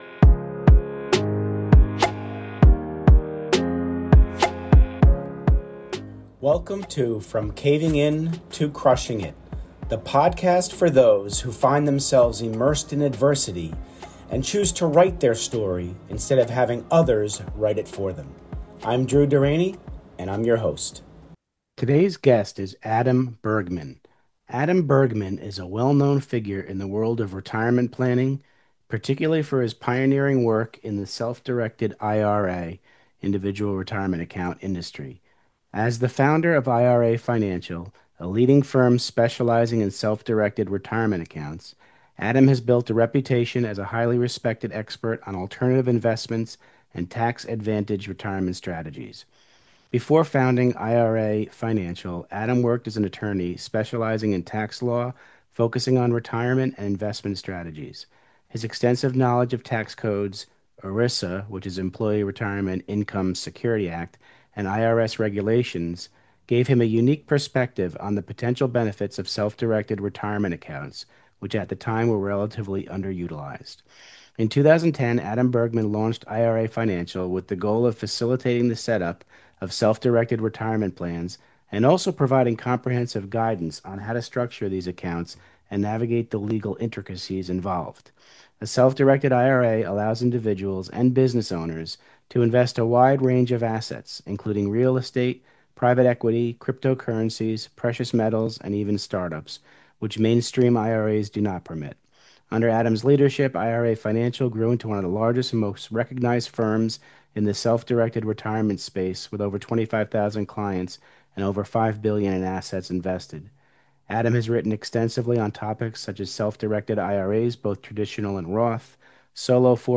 Introduction and Purpose of the Interview